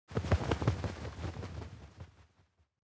دانلود آهنگ پرنده 51 از افکت صوتی انسان و موجودات زنده
جلوه های صوتی
دانلود صدای پرنده 51 از ساعد نیوز با لینک مستقیم و کیفیت بالا